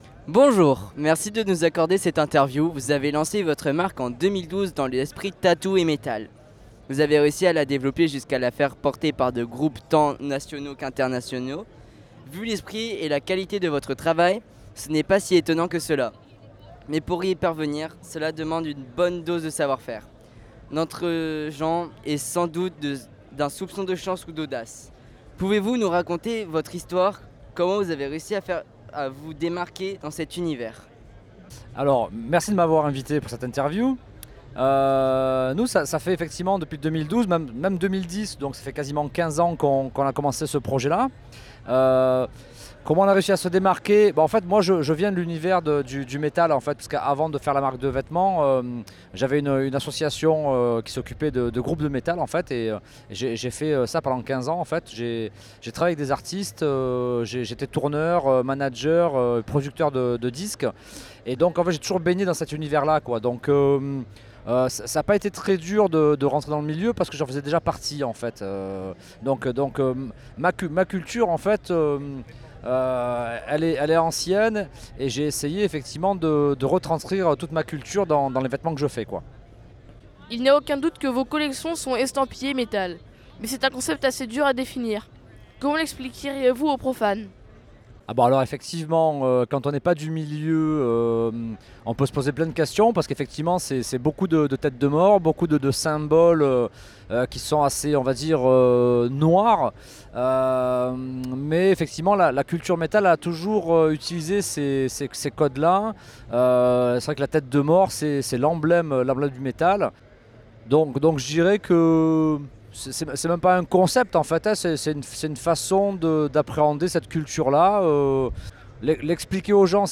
interview_hyraw_les_petits_metalleux.flac